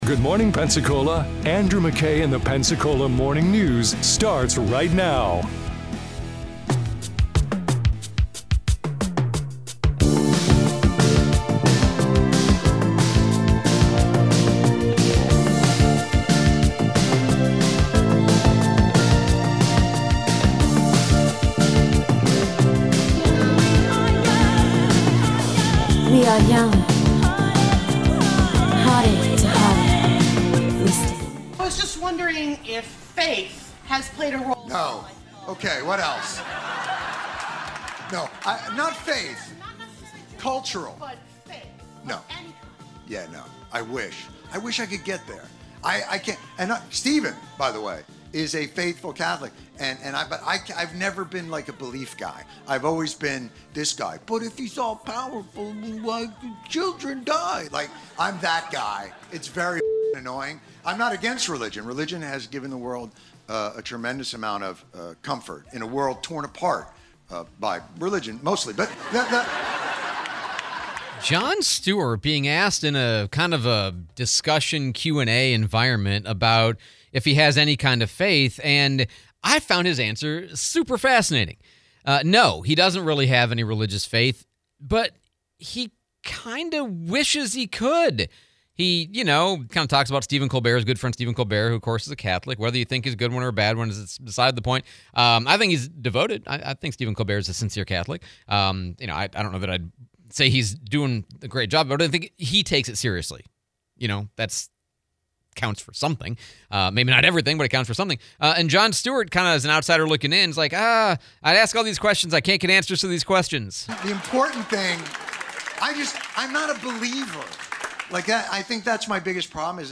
Jon Stewart Interview, Escambia County Sheriff Chip Simmons